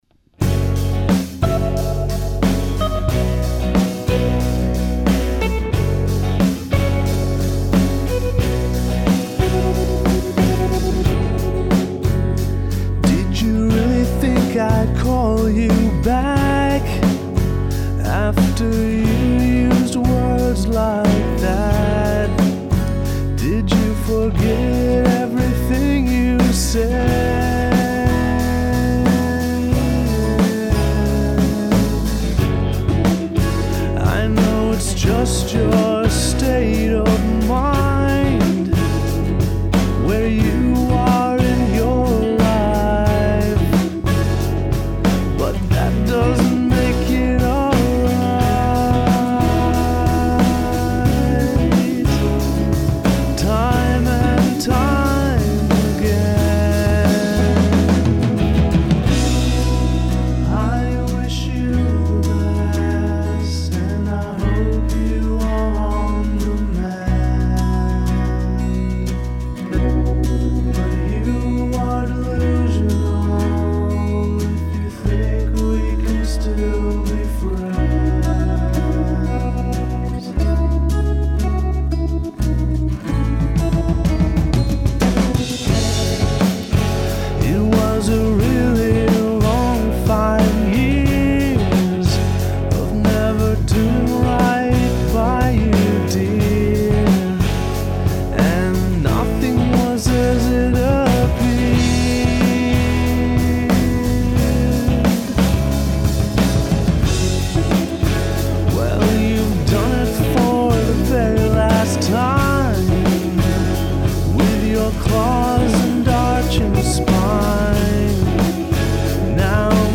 japanese mustang copy + silvertone 1464 = helocopter